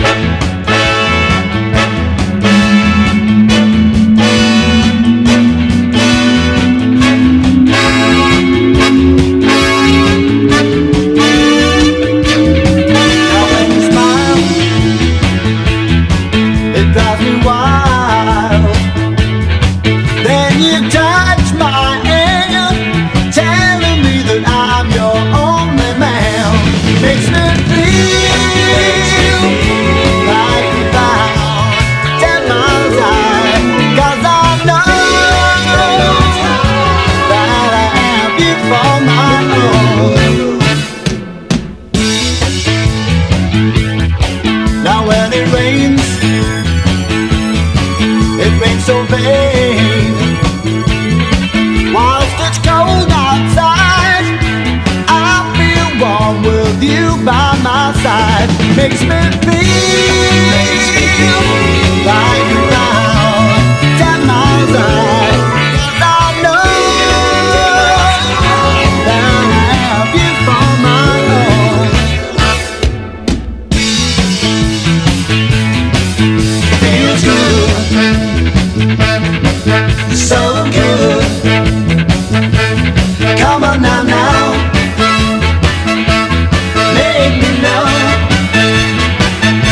ROCK / 60'S / ROCK & ROLL / RHYTHM & BLUES / DRUM BREAK
英国ロック重要人物が結集したロックンロール・セッション！
フラット・ロックした痛快ミッド・テンポ・ロックンロール
粘り気のあるギター、凛としたピアノ・タッチ、切なくこみ上げるヴォーカルで泣かせる